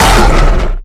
giant_attack_3.ogg